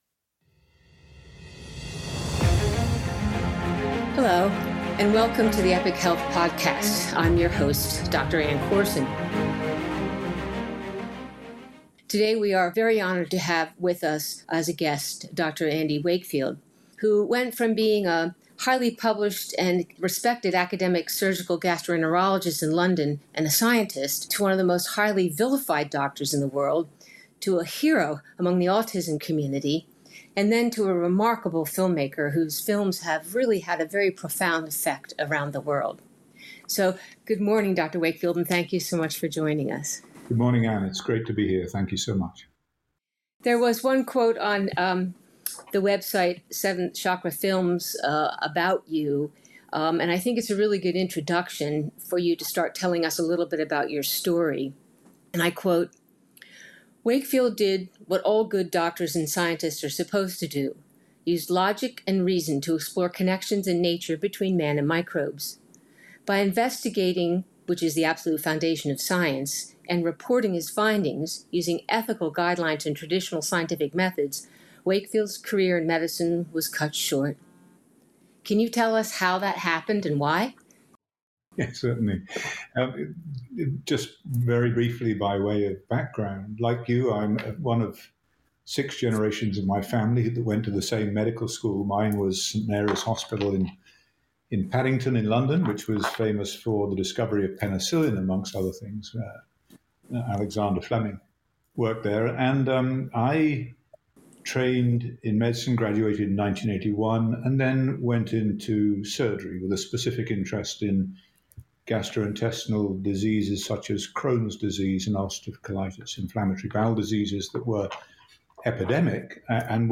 In this podcast, Dr. Wakefield tells us the stories behind his films ’Who Killed Alex Spourdalakis?‘, ’Vaxxed: From Cover-Up to Catastrophe’, and ’1986, The Act.’